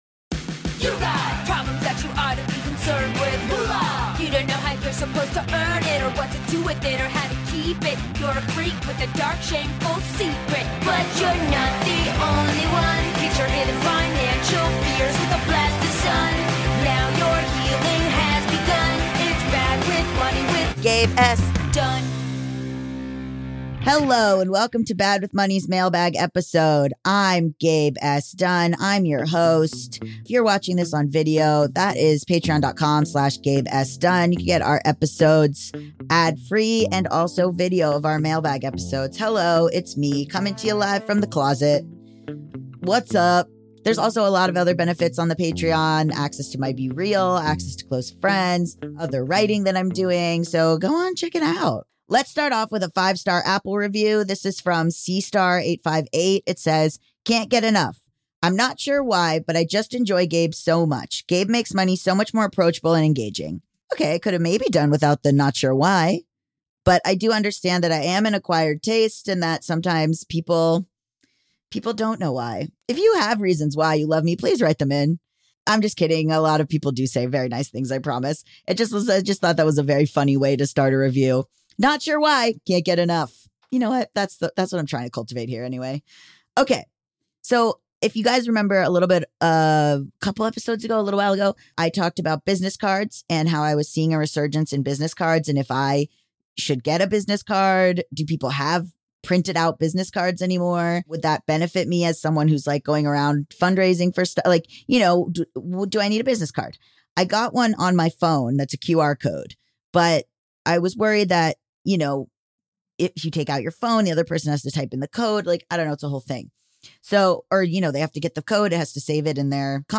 Mailbag